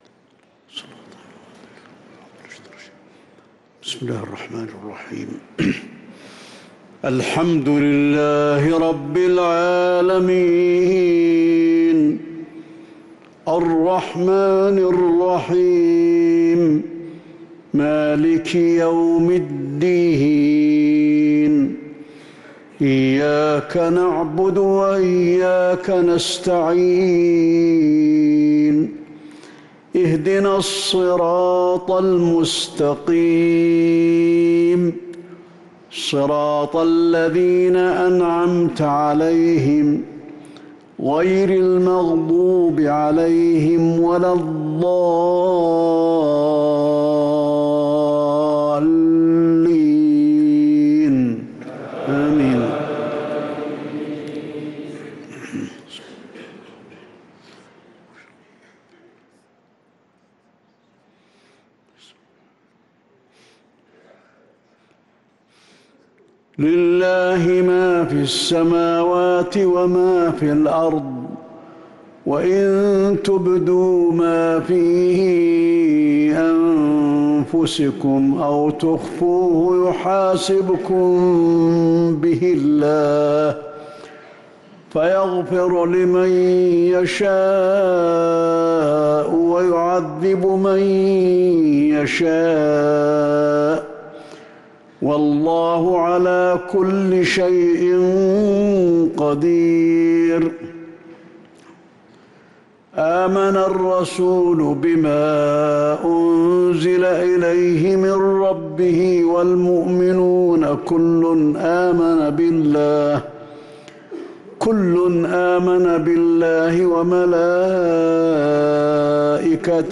صلاة العشاء للقارئ علي الحذيفي 6 جمادي الآخر 1445 هـ